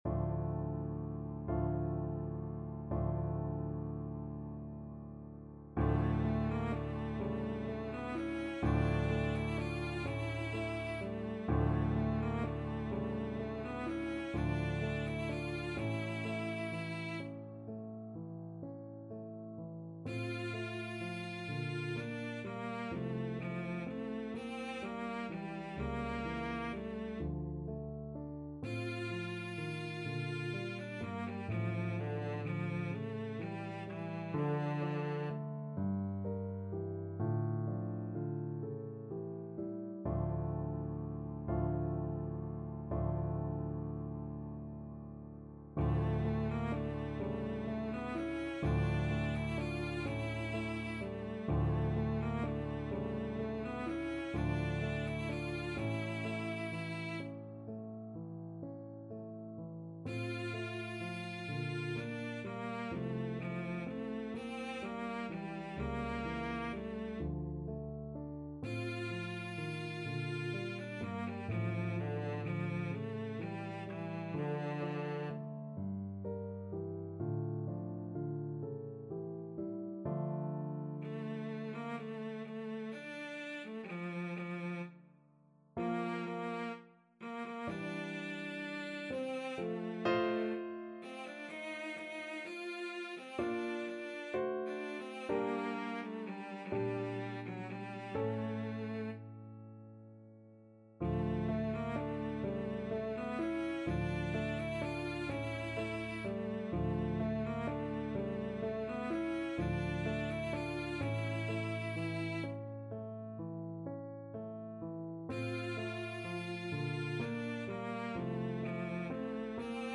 Classical Schubert, Franz Der Abend, D.108 Cello version
F major (Sounding Pitch) (View more F major Music for Cello )
6/8 (View more 6/8 Music)
. = 42 Andante con moto (View more music marked Andante con moto)
Classical (View more Classical Cello Music)